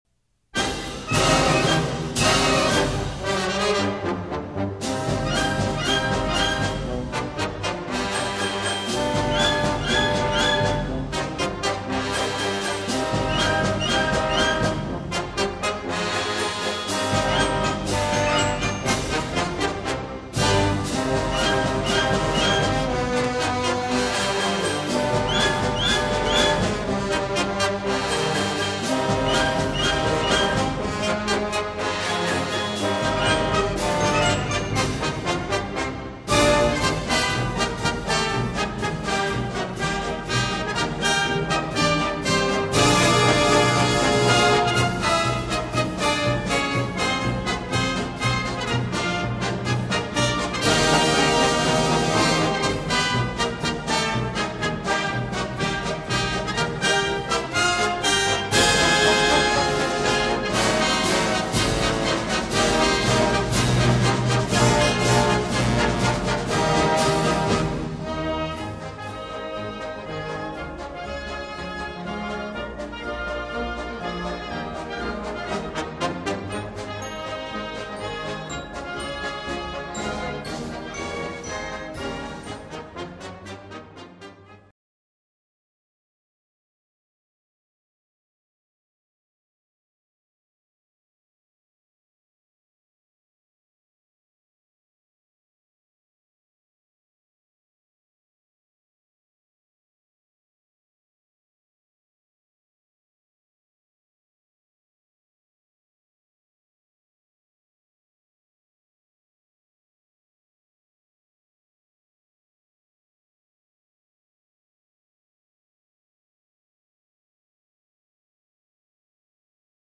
Flute/Piccolo
Oboe
Clarinet 1
Bass Clarinet
Bassoon
Alto Sax 1
Tenor Sax
Baritone Sax
Trumpet 1
Horn in F 1-2
Trombone 1&2
Euphonium TC
Tuba
Timpani/Bells
Drums
Cymbals
Bringing Classic Marches to the Bandstand